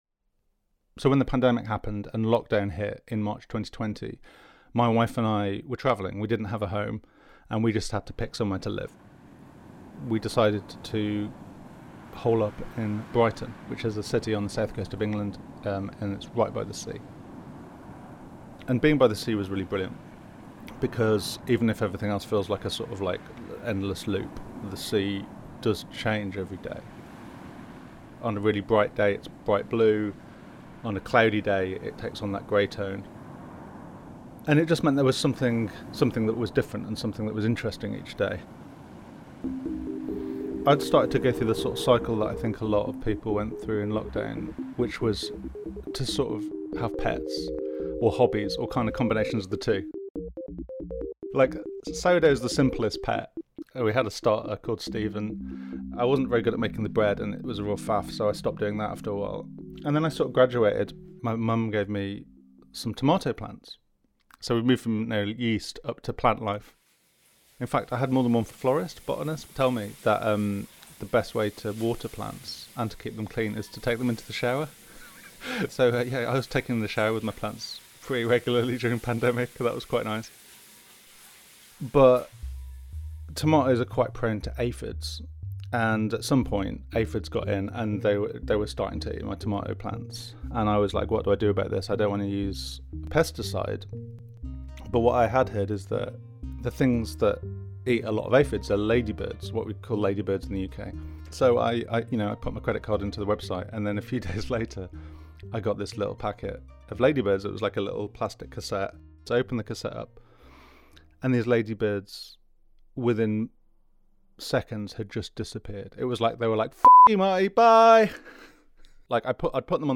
• Exhibits a moment of audible ALL CAPS
• (a moment of emphasis that stands out from the rest of the story)